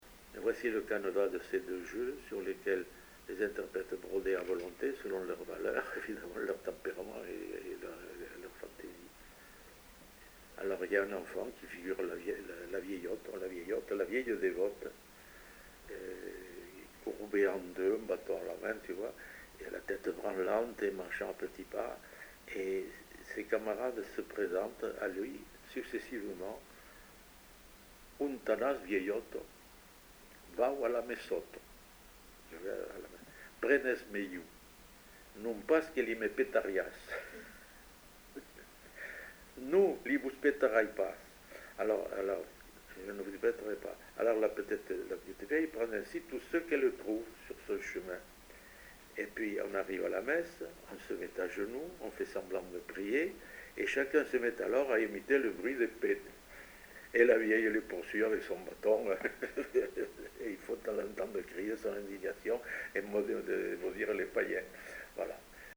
Lieu : Saint-Sauveur
Genre : forme brève
Effectif : 1
Type de voix : voix d'homme
Production du son : récité
Classification : formulette enfantine